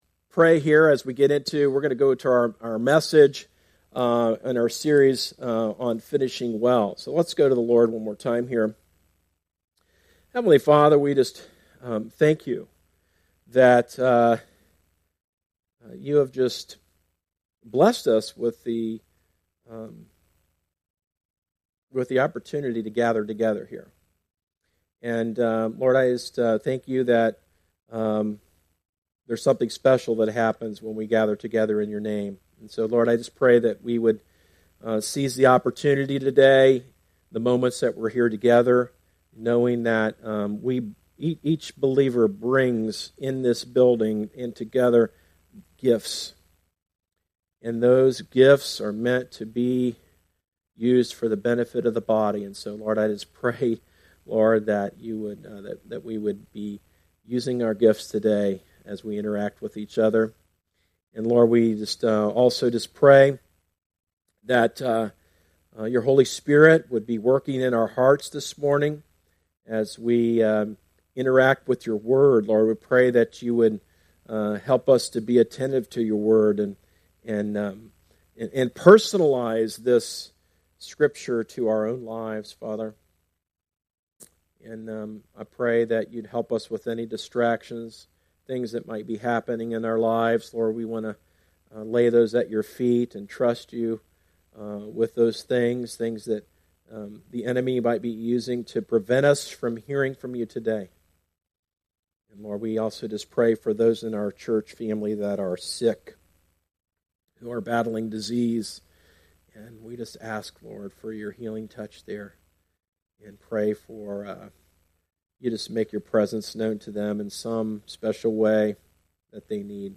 A message from the series "Finishing Well."